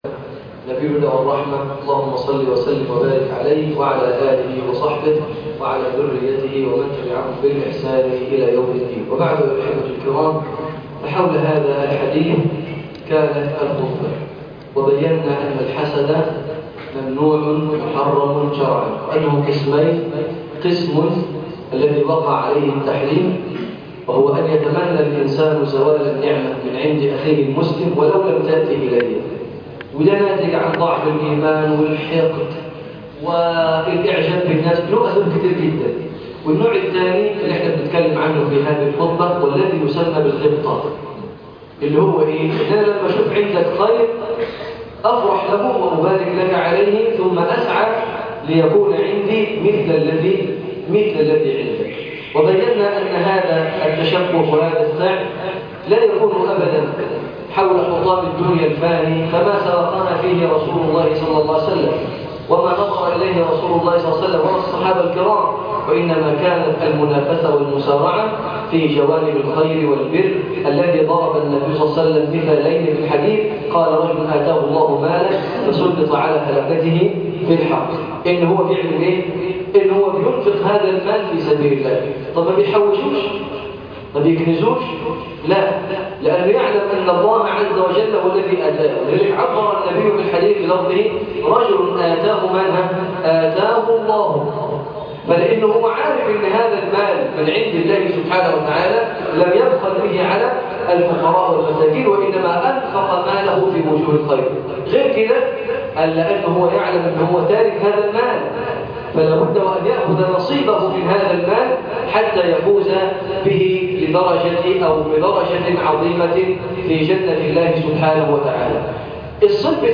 لا حسد الا في اثنتين - تكملة بعد الخطبة